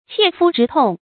切膚之痛 注音： ㄑㄧㄝ ˋ ㄈㄨ ㄓㄧ ㄊㄨㄙˋ 讀音讀法： 意思解釋： 切膚：切身。